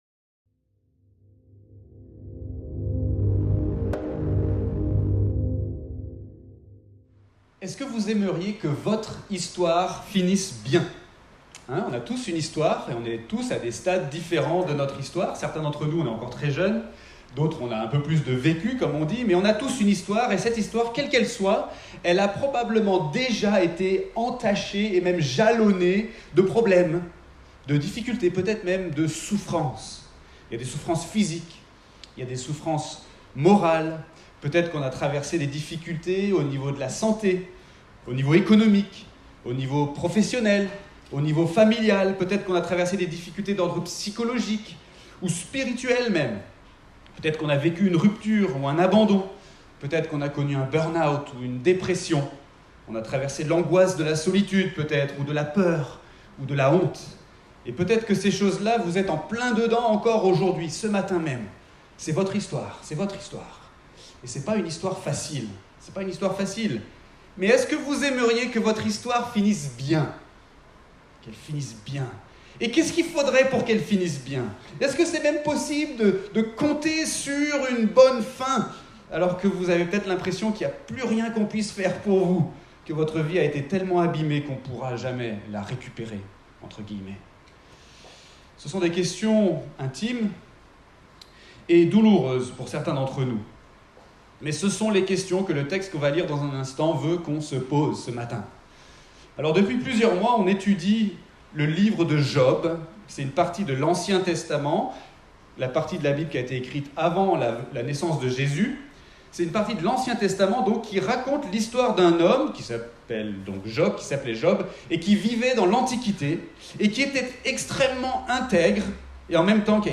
Prédications textuelles